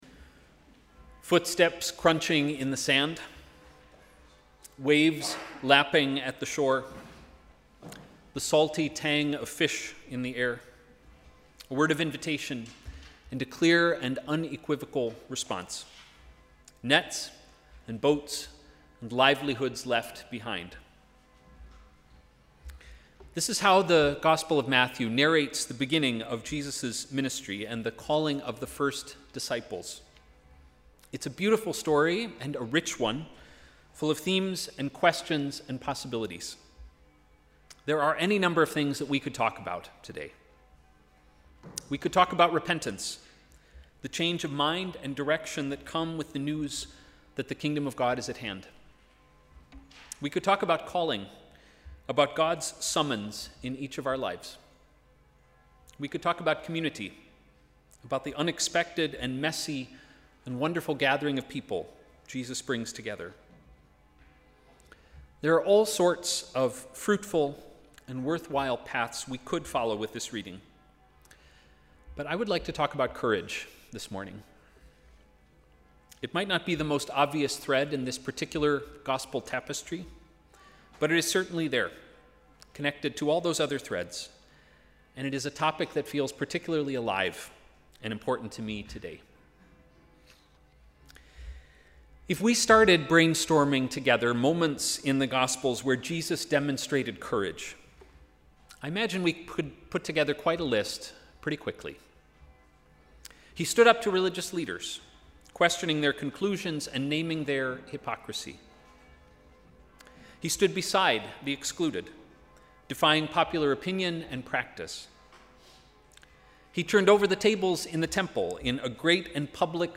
Sermon: ‘Ordinary courage’